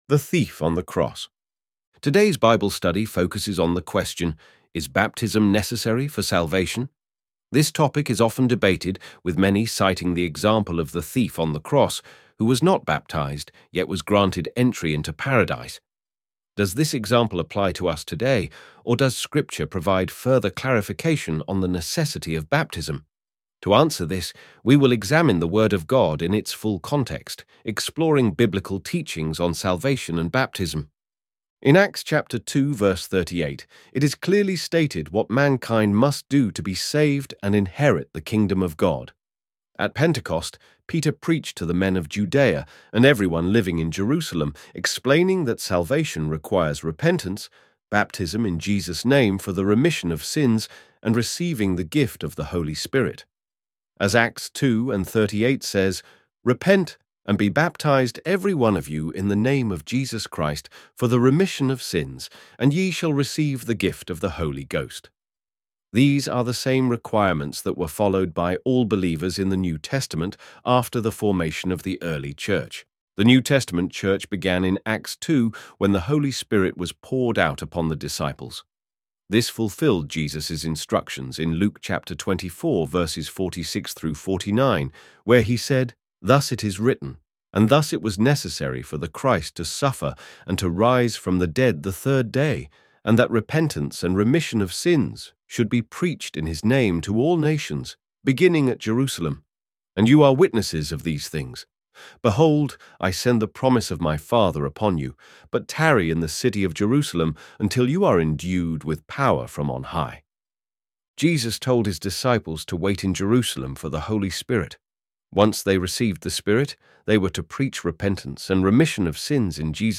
Bible Studies
ElevenLabs_NOW-1.mp3